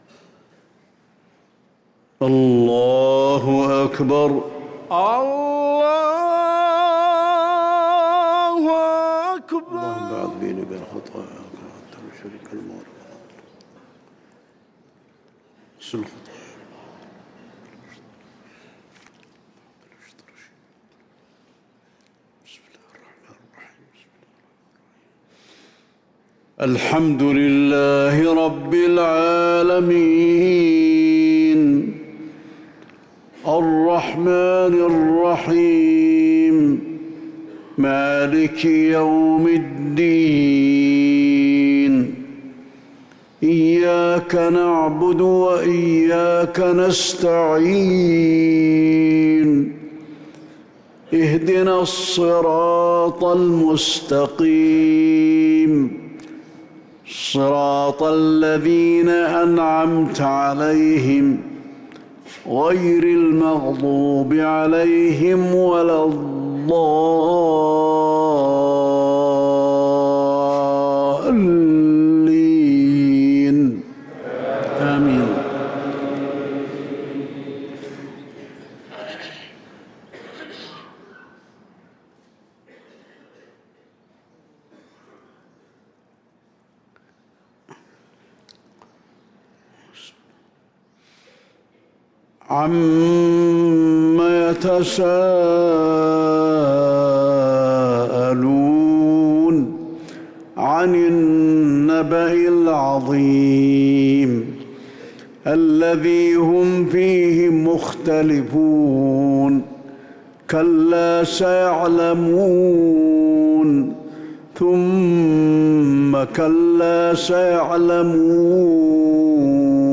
صلاة الفجر للقارئ علي الحذيفي 15 جمادي الآخر 1445 هـ
تِلَاوَات الْحَرَمَيْن .